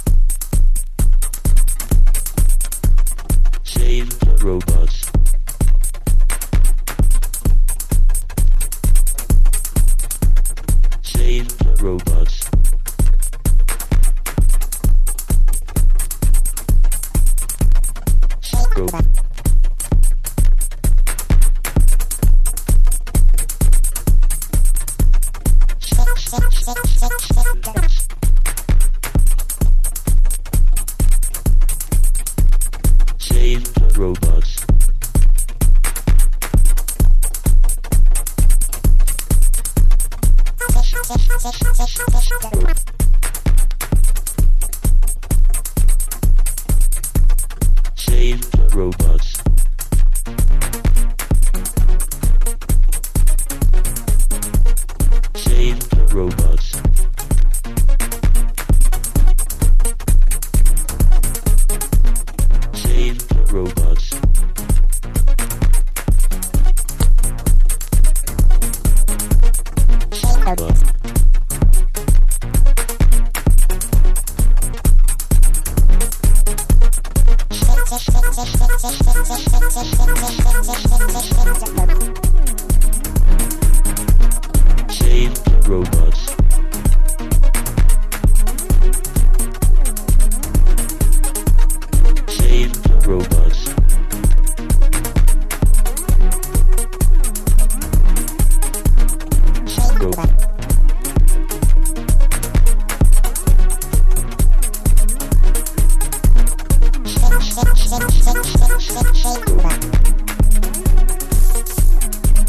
シンプルな素材でひねてるレトロフューチャーなジャッキン/エレクトロトラック。頭から尻尾までピッチシフトで攻める